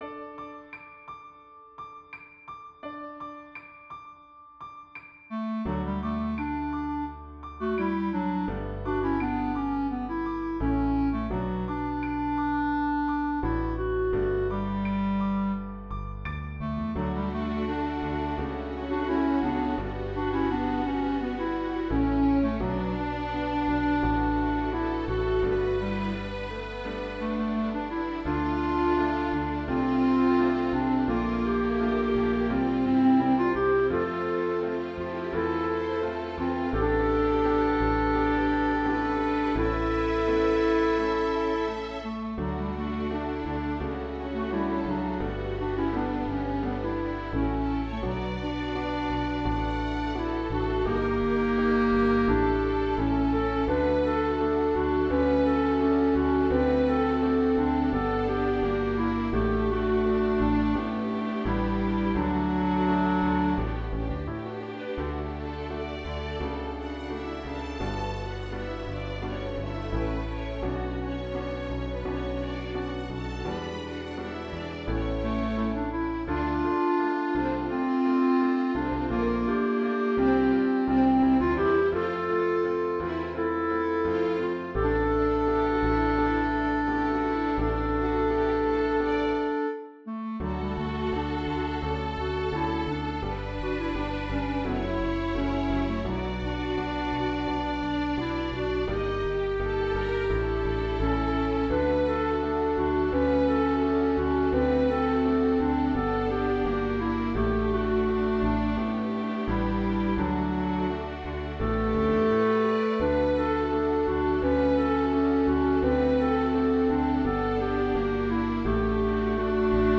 + počítačové verzie niektorých našich úprav 😉